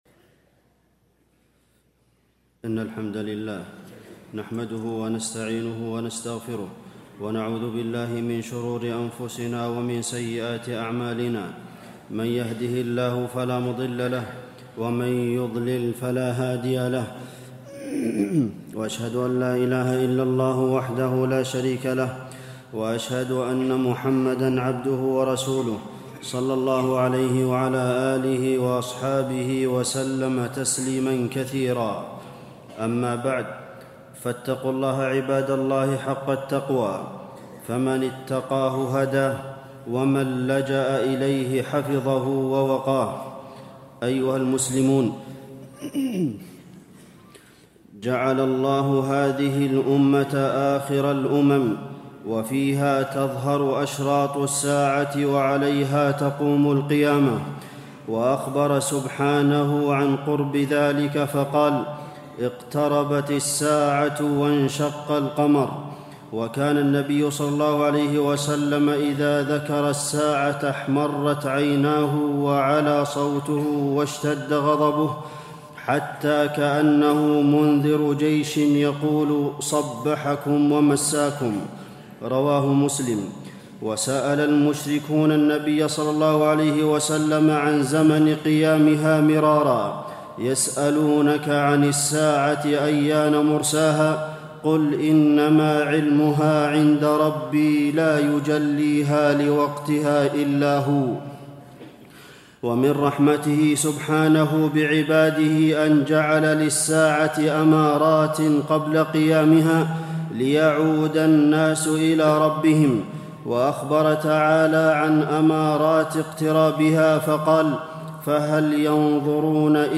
تاريخ النشر ١٢ محرم ١٤٣٥ هـ المكان: المسجد النبوي الشيخ: فضيلة الشيخ د. عبدالمحسن بن محمد القاسم فضيلة الشيخ د. عبدالمحسن بن محمد القاسم من أشراط الساعة الكبرى المسيح الدجال